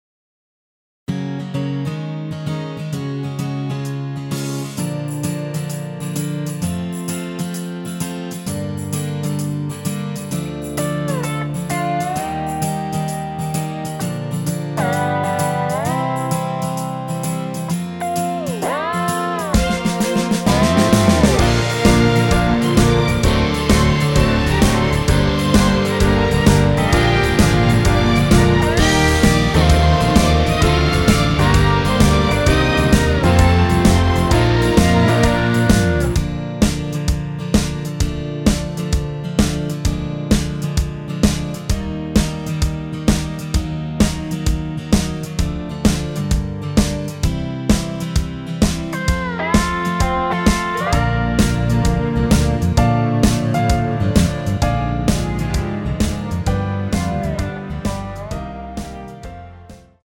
전주 없는 곡이라 전주 2마디 만들어 놓았습니다.
엔딩이 페이드 아웃이라라이브 하시기 편하게 엔딩을 만들어 놓았습니다.
Bb
앞부분30초, 뒷부분30초씩 편집해서 올려 드리고 있습니다.
중간에 음이 끈어지고 다시 나오는 이유는